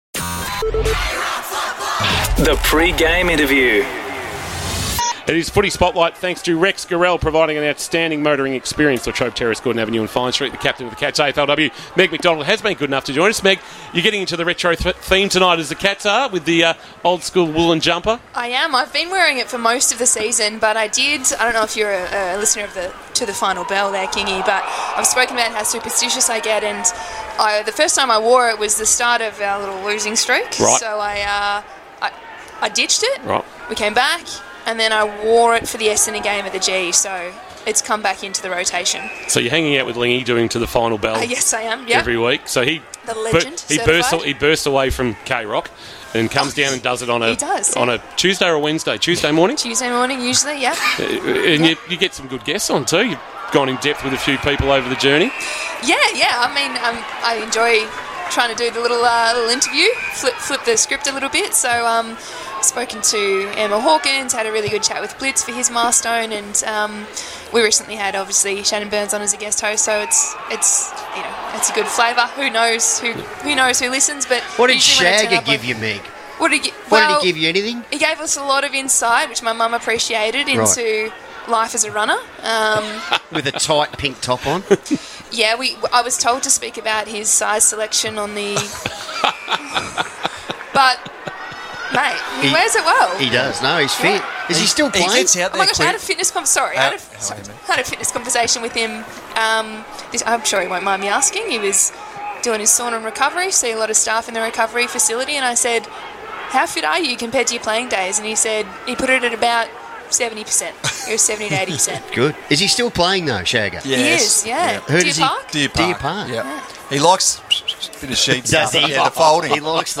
2024 - AFL - Round 19 - Geelong vs. Western Bulldogs: Pre-match interview - Meg McDonald (Geelong Cats AFLW captain)